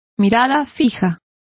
Complete with pronunciation of the translation of gaze.